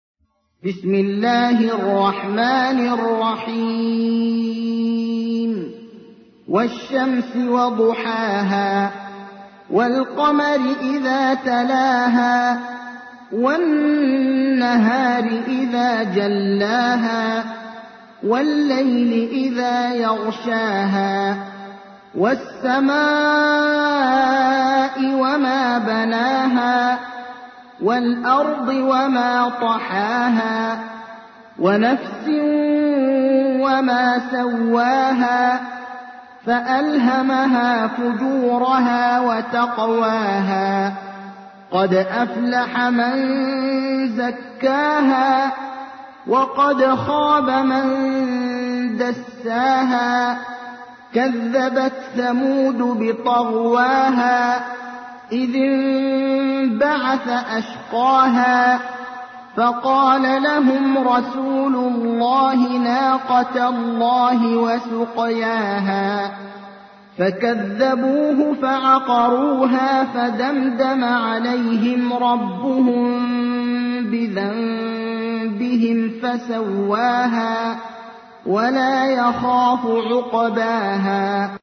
تحميل : 91. سورة الشمس / القارئ ابراهيم الأخضر / القرآن الكريم / موقع يا حسين